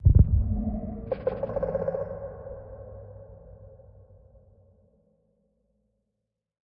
Minecraft Version Minecraft Version snapshot Latest Release | Latest Snapshot snapshot / assets / minecraft / sounds / mob / warden / nearby_closer_2.ogg Compare With Compare With Latest Release | Latest Snapshot